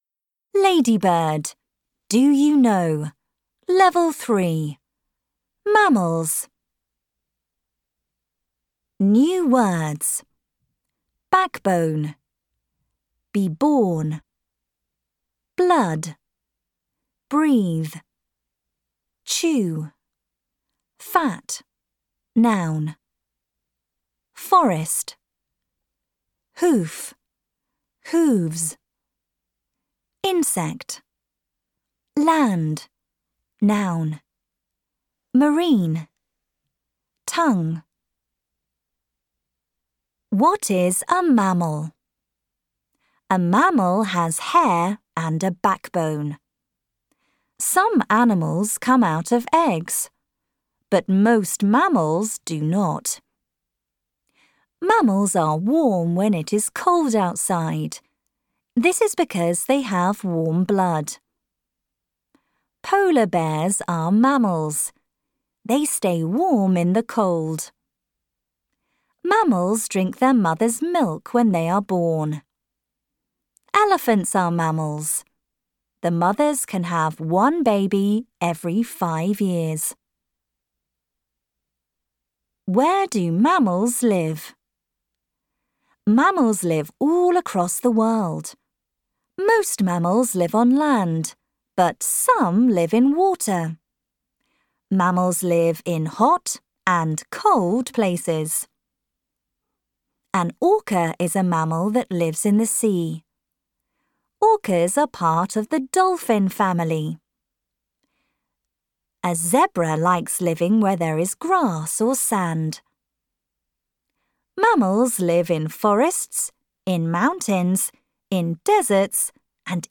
Audio UK